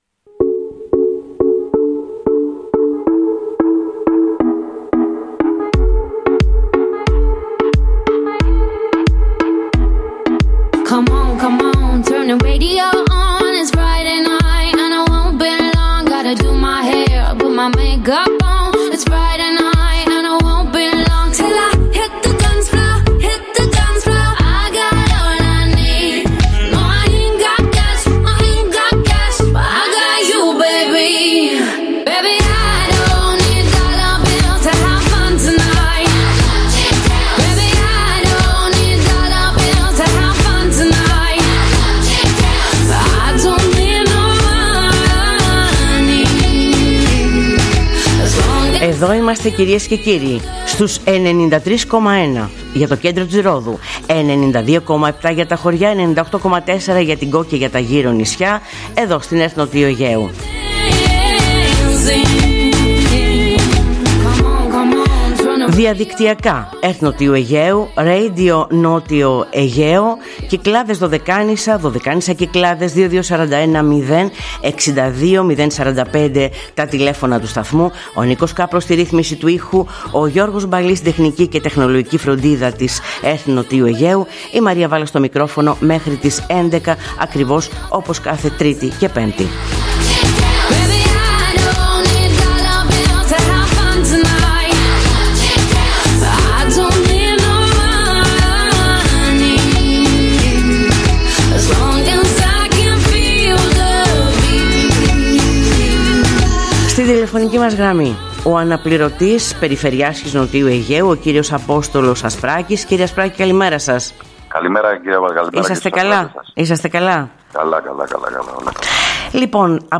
Στη σημερινή εκπομπή της Τρίτης 13 Μαΐου, στο πρώτο μέρος ακούμε τη συνέντευξη του Αν. Περιφερειάρχη Ν. Αιγαίου κ. Απόστολου Ασπράκη αναφορικά με την αύξηση των ναύλων στις μεταφορές λόγω χρήσης του καυσίμου MGO, σύμφωνα με οδηγία της Ευρώπης, την πορεία των έργων στη Ρόδο μετά τις αλλεπάλληλες φυσικές καταστροφές του χειμώνα και την παρουσίαση στη Χάλκη, της εφαρμογής e_cardio στα μικρά και απομακρυσμένα νησιά, για έγκαιρη παρέμβαση σε καρδιακά επεισόδια.